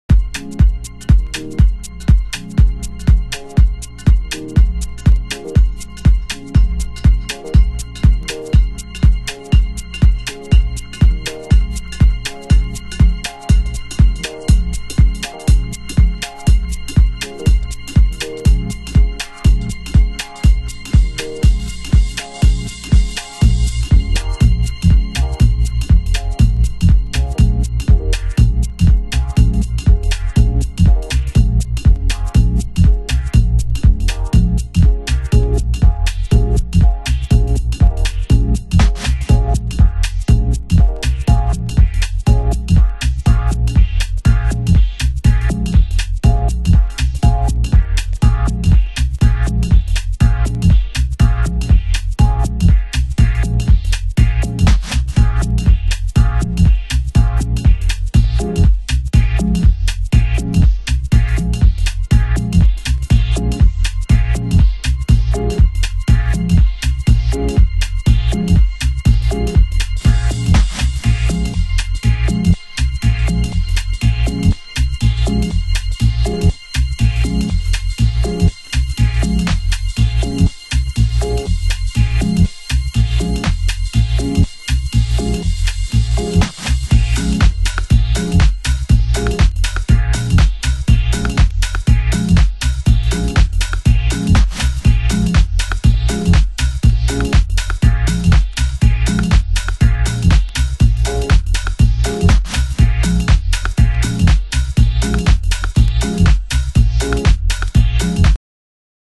JAZZY